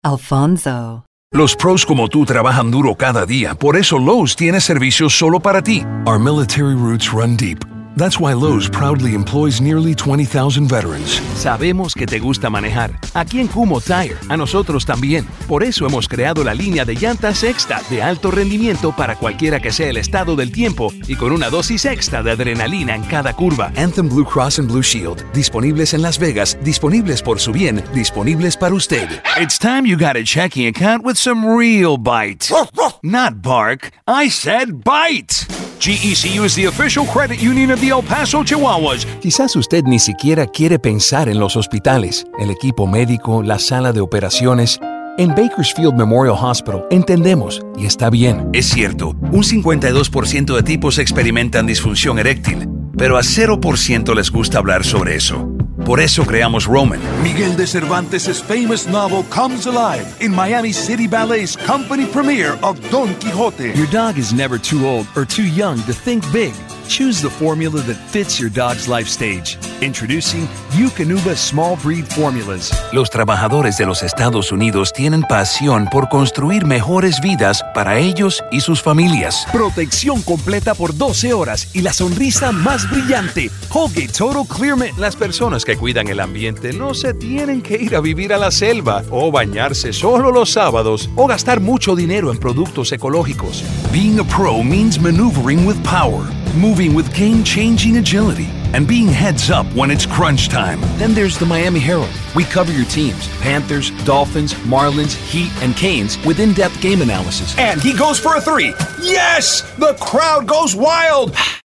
Versatile and refined-your Everyman in both English and Spanish.
Showcase Demo english-showcase, spanish-showcase